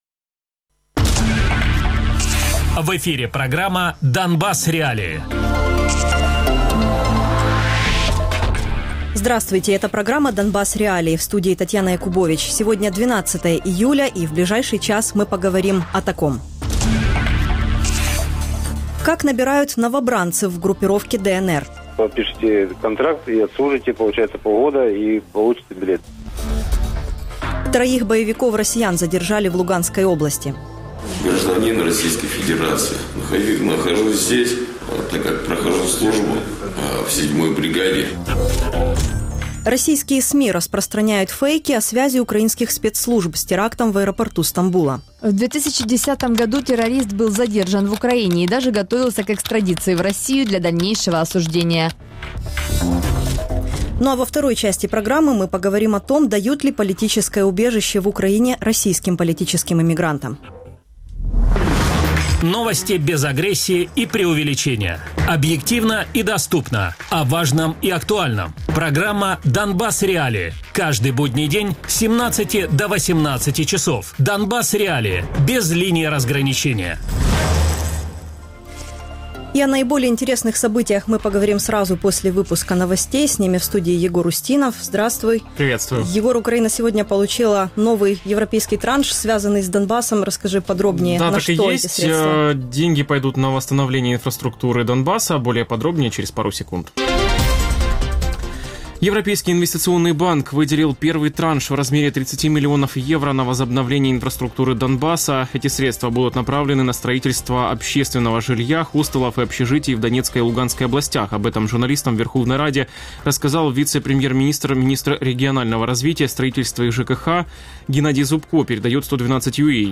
Радіопрограма «Донбас.Реалії» - у будні з 17:00 до 18:00.